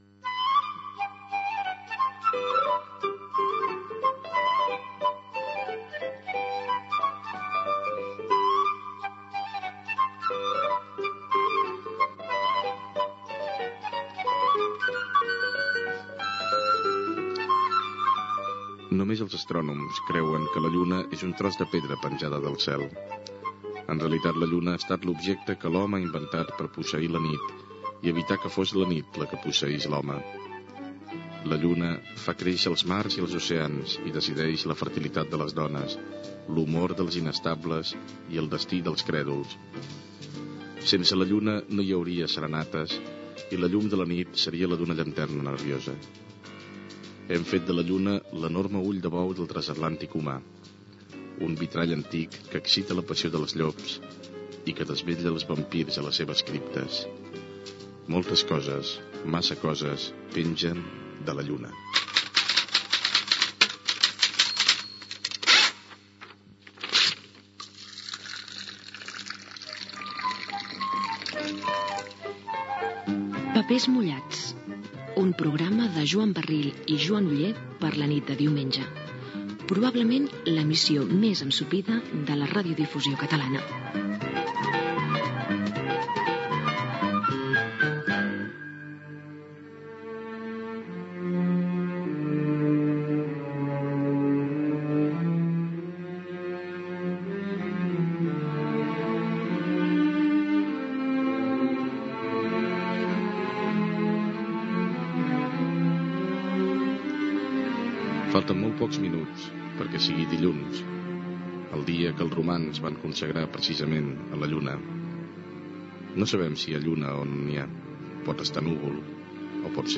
Escrit sobre la Lluna, careta del programa, represa de l'escrit sobre la Lluna, tema musical, les influències de la Lluna en la llenya i les persones